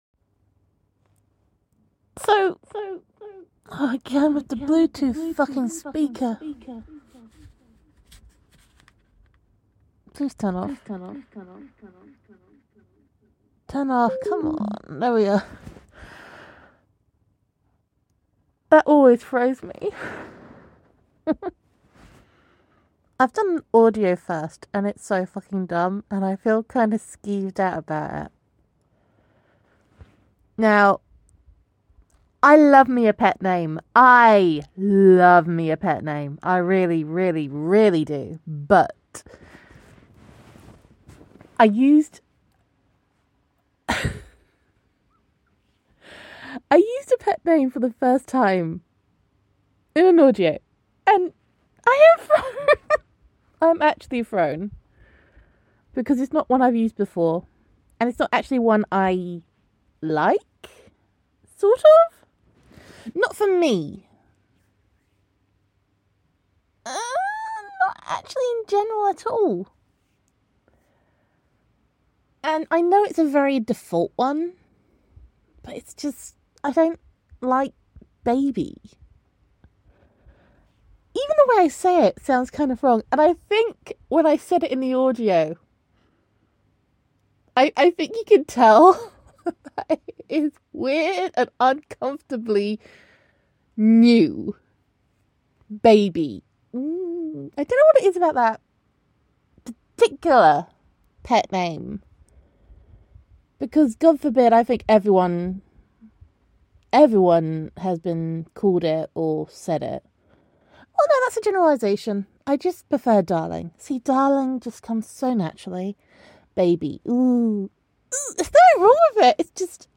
That echo tickled me--I thought it was deliberate!